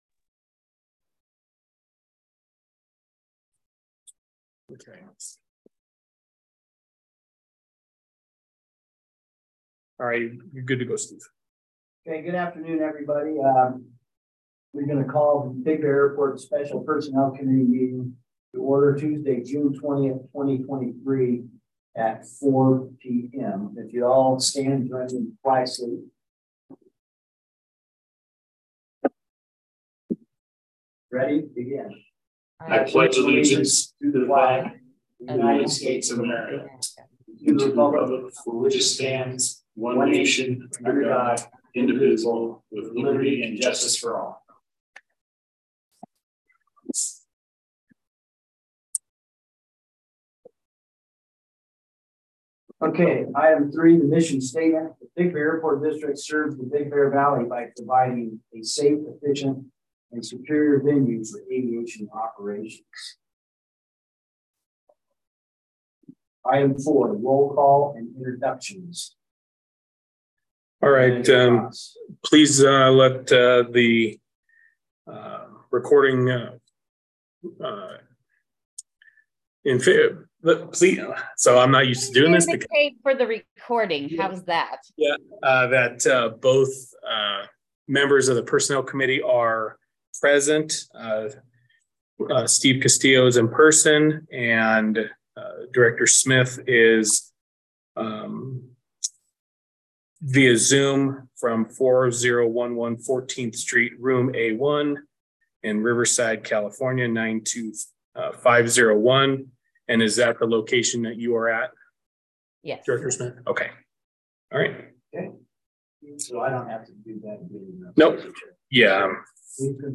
Board Special Personnel Committee Meeting